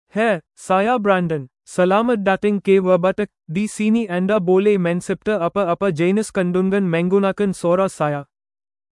Brandon — Male Malayalam (India) AI Voice | TTS, Voice Cloning & Video | Verbatik AI
MaleMalayalam (India)
Brandon is a male AI voice for Malayalam (India).
Voice sample
Brandon delivers clear pronunciation with authentic India Malayalam intonation, making your content sound professionally produced.